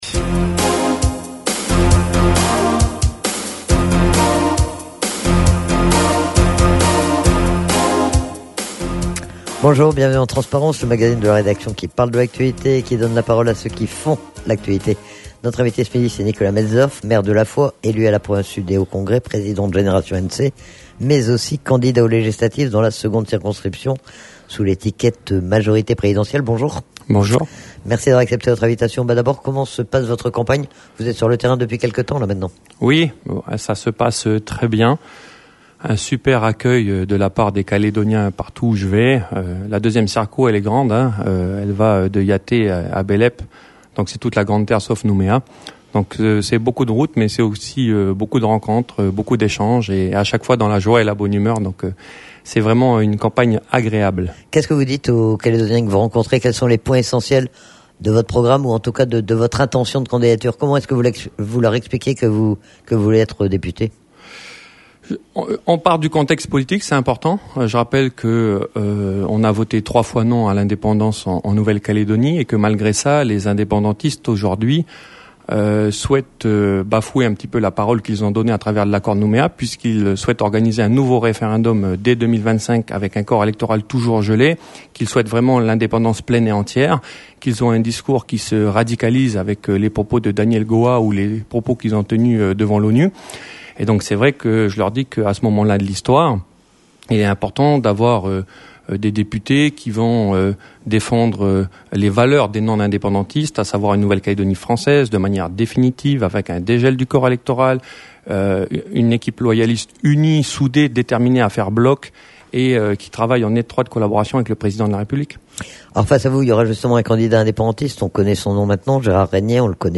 Nicolas Metzdorf, le candidat de la majorité présidentielle dans la seconde circonscription. Il est interrogé sur les raisons de sa candidature, sur ses motivations et son programme mais aussi le contexte de cette élection et notamment sur l'union loyaliste.